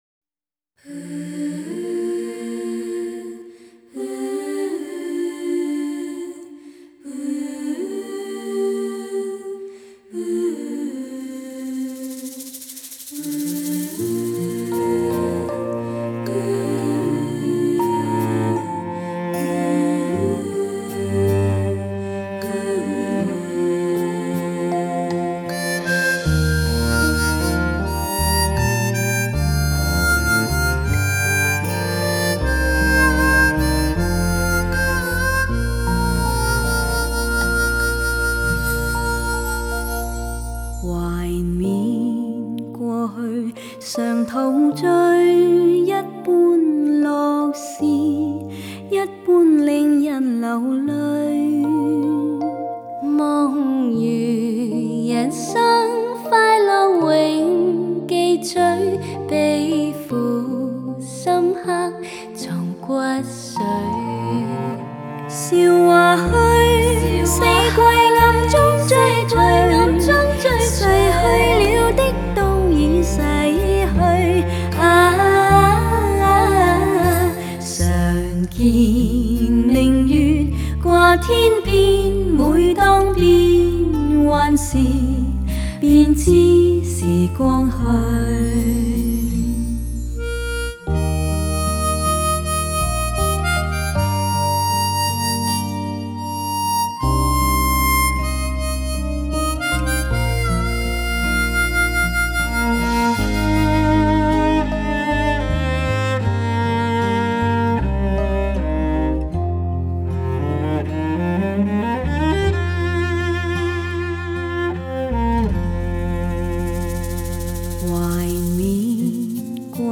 让深情的歌声，再度唤起你无限的思念
国内HI-FI唱片界为数不多的由女声组合演唱的粤语专辑，
整张专辑的曲目极具怀旧感，
乐风清新但张驰有度，
人声甜美又玲珑剔透，
音效和谐似珠圆玉润，